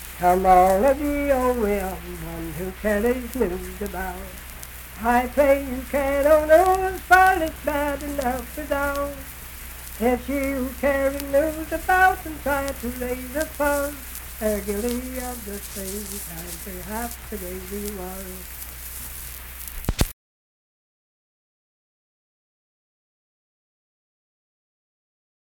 Unaccompanied vocal music
Performed in Ivydale, Clay County, WV.
Voice (sung)